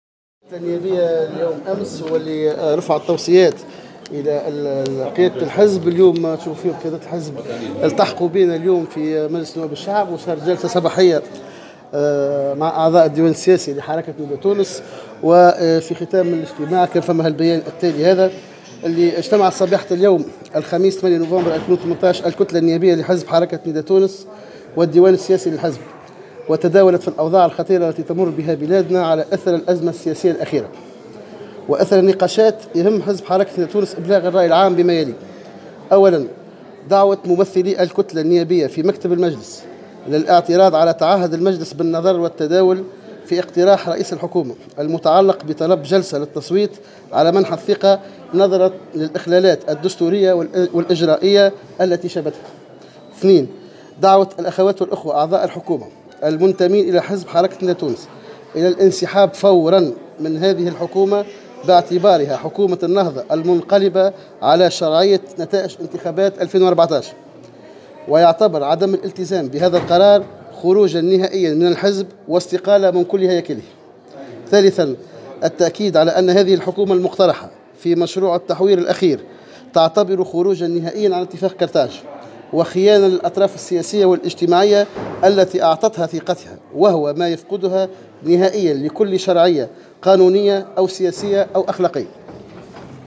وأكد رئيس الكتلة سفيان طوبال في تصريح صحفي لـ "الجوهرة أف أم" ما جاء في البيان الذي أصدرته الكتلة اثر اجتماع في وقت سابق من اليوم للكتلة النيابية والديوان السياسي لحزب نداء تونس لتداول ما وصفته بـ" الاوضاع الخطيرة التي تمر بها بلادنا على اثر الازمة السياسية الاخيرة".